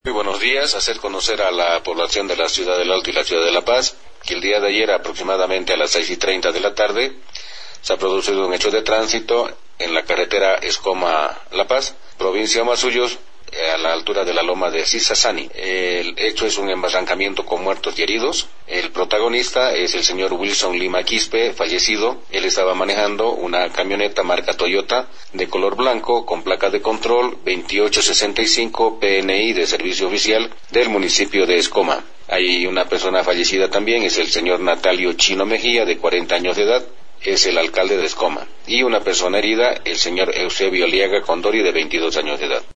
Pablo García, Director de Transito de El Alto